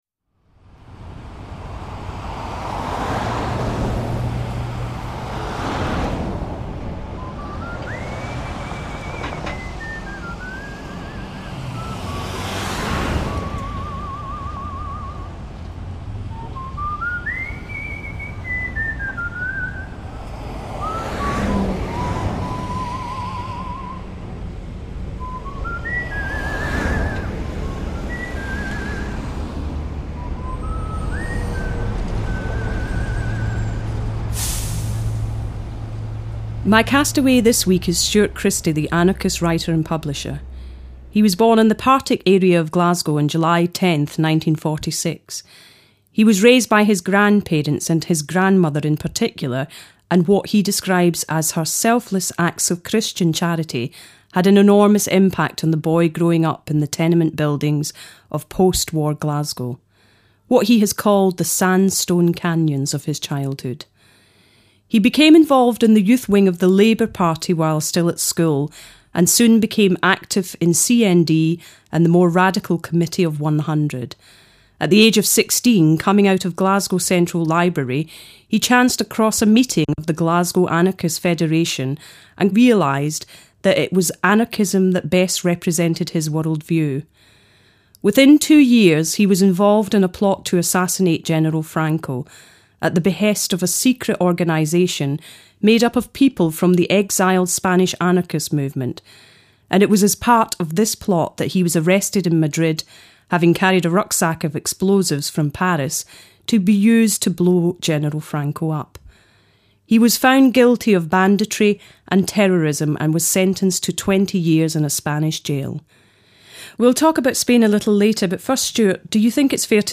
Re-calibrate your DAB, re-align your satellite, and you’ll find a world where Desert Island Dissidents is a popular radio show featuring interviews with ex members of the Angry Brigade (a pocket-sized left wing terrorist group active in the early 70s), where the Arab Spring is brought uncomfortably close to home, and where Sunday evening TV features Time Watch-esque explorations of corners of the Earth’s surface we’d rather remained off-limits and buried for good.